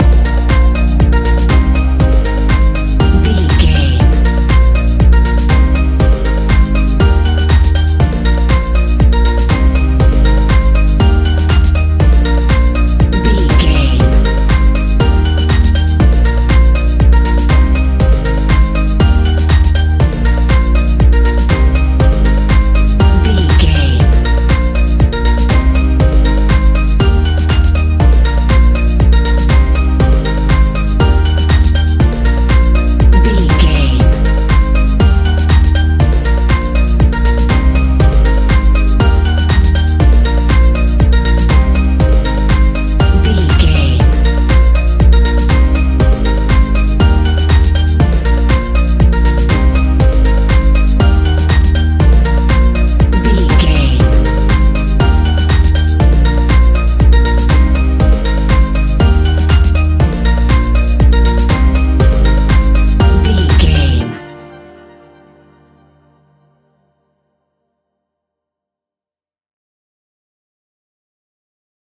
Ionian/Major
D♯
house
electro dance
synths
trance
instrumentals